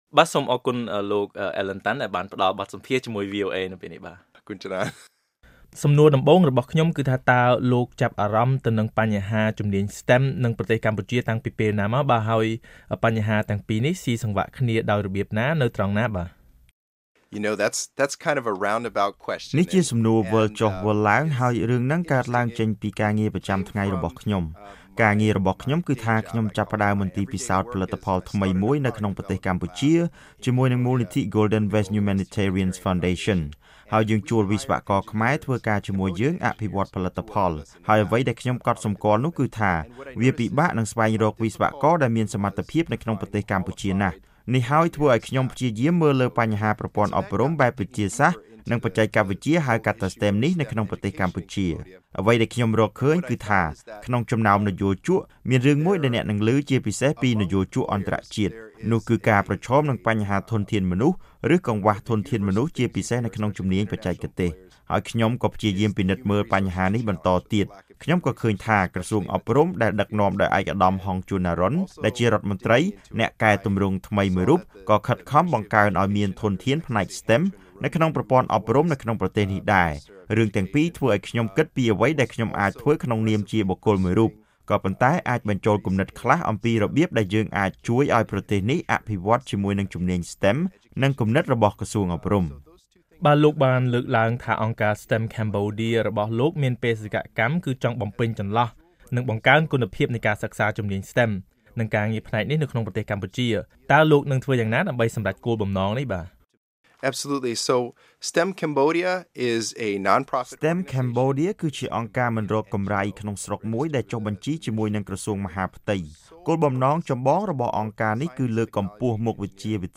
បទសម្ភាសន៍ VOA៖ ខ្មែរអាមេរិកាំងថាជំនាញ STEM ចាំបាច់សម្រាប់វិស័យបច្ចេកវិទ្យាជាន់ខ្ពស់របស់កម្ពុជា